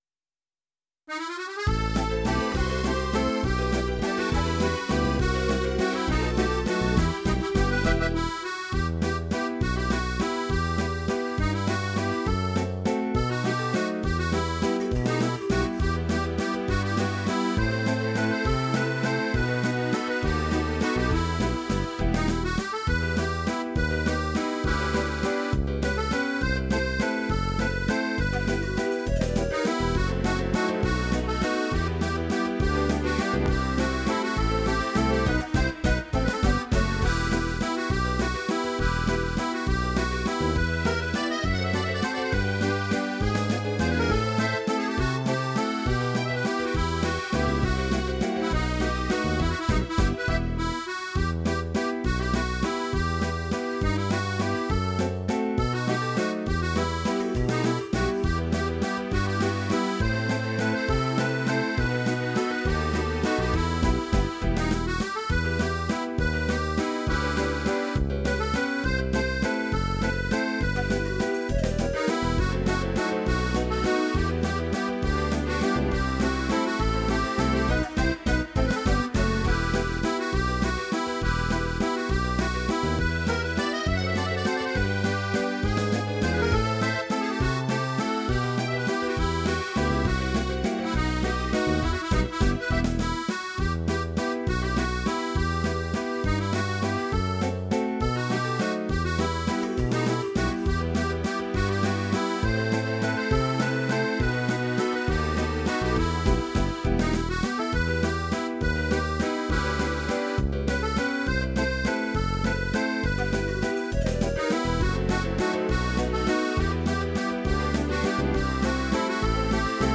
versión instrumental multipista